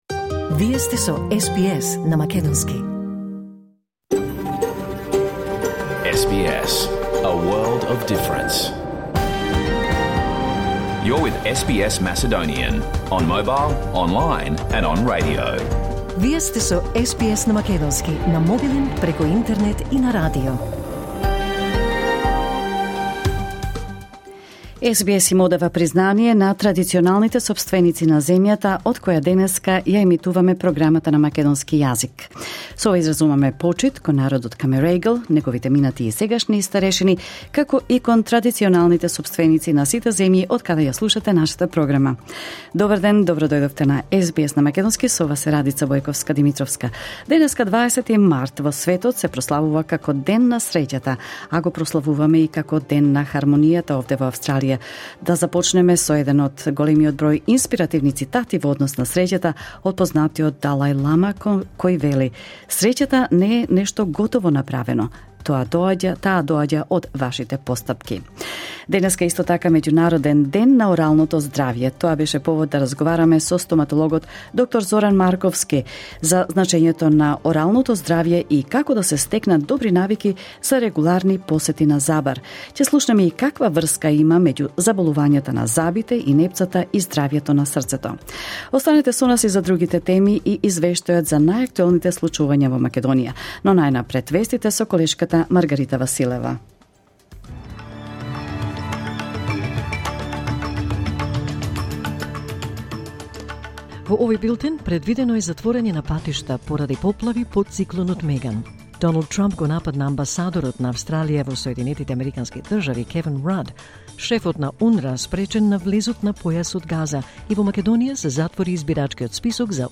SBS Macedonian Program Live on Air 20 March 2024